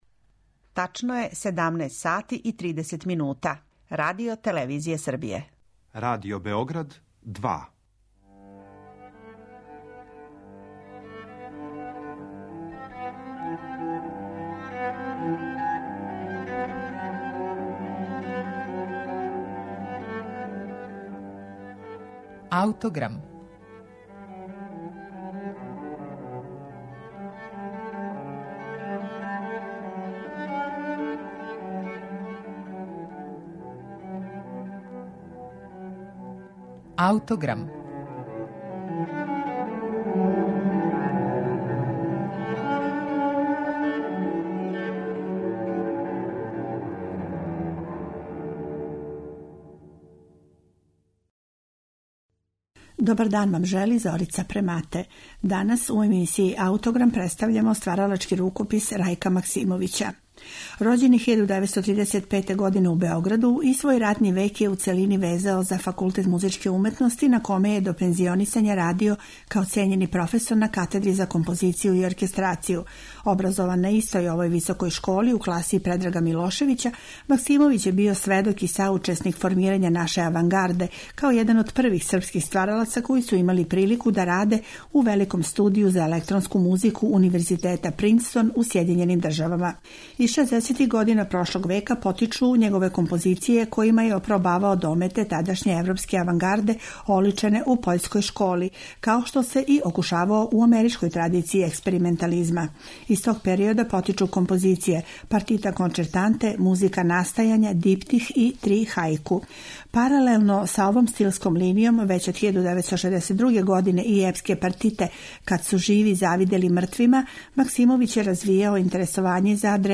за гудаче
за гудачки оркестар